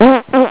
fart.wav